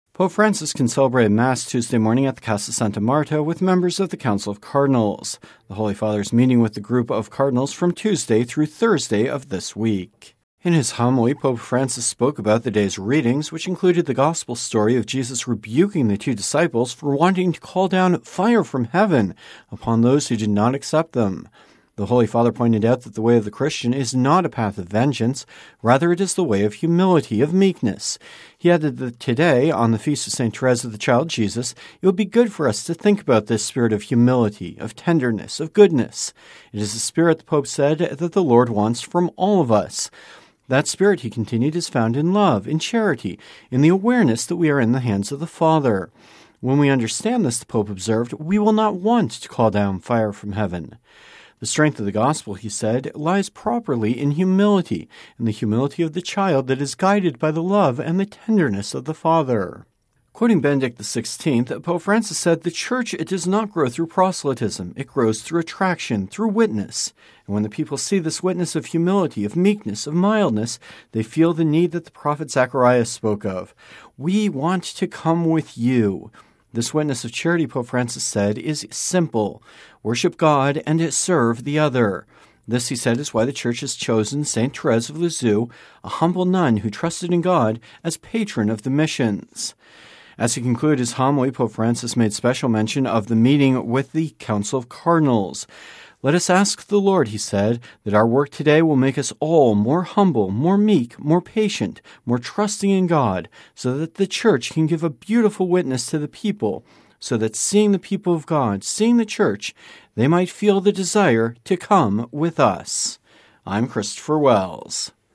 (Vatican Radio) Pope Francis concelebrated Mass this morning at the Casa Santa Marta with members of the Council of Cardinals.